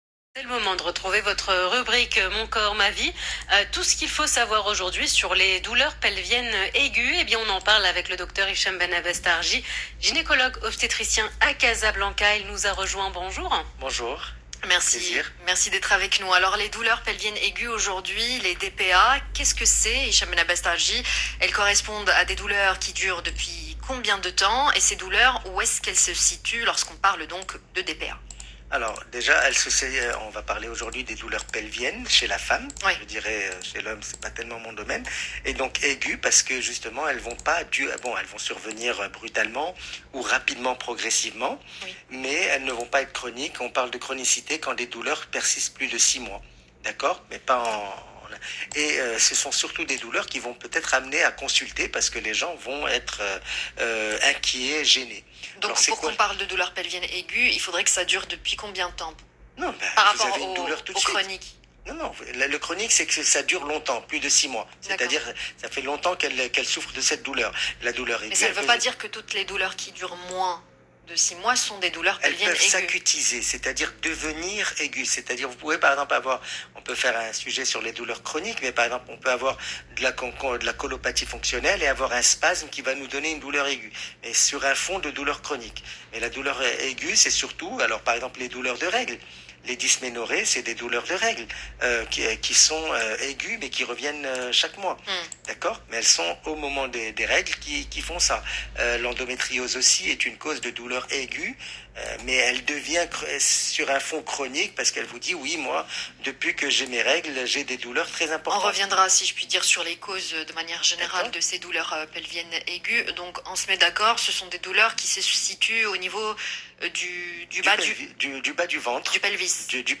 Plus d’explications dans cette interview de l’Heure Essentielle sur Luxe Radio du 12 avril 2022